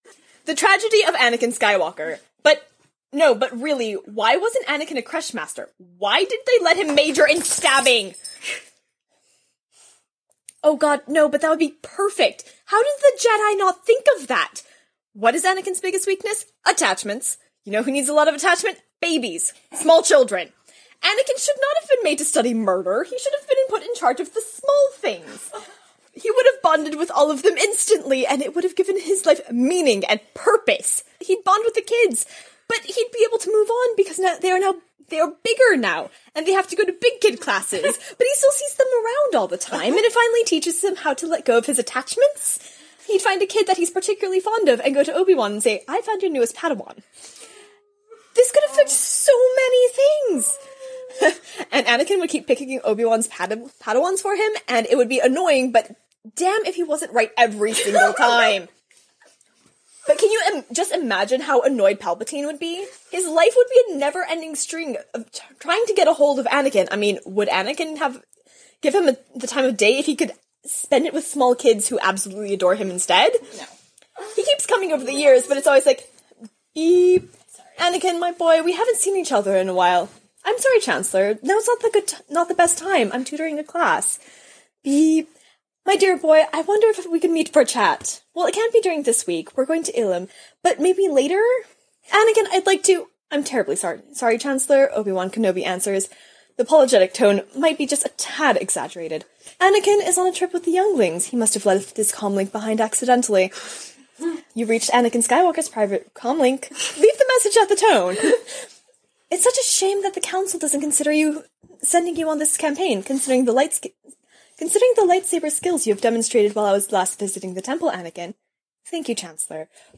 Live reading of a tumblr post, there are no apologies.